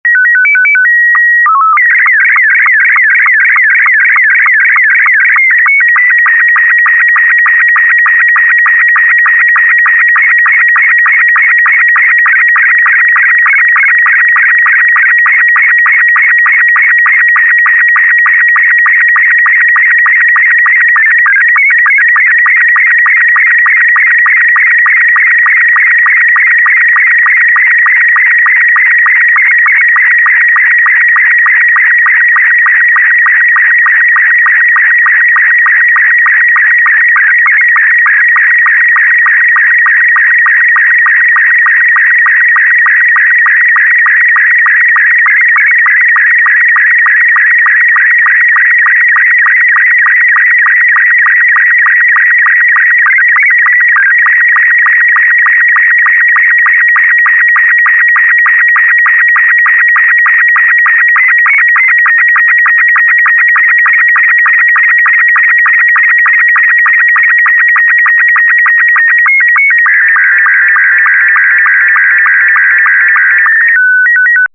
Robot72 is a simpler mode than Robot36 with a scan line that
- 1200 Hz for 9ms
- 1500 Hz for 3ms
- 2300 Hz for 4.5ms
Robot_72.mp3